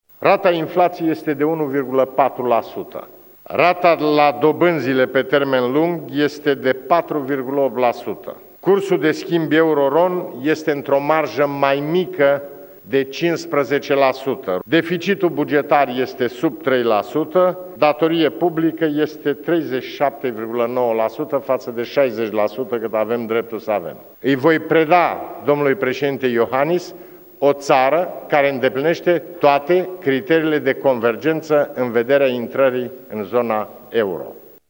Aşa a declarat, în această seară, preşedintele Traian Băsescu, la bilanţul celor două mandate ca şef al statului.